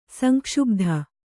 ♪ sankṣubdha